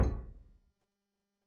Heavy Door Slam
A heavy wooden door slamming shut with a powerful thud and frame rattle
heavy-door-slam.mp3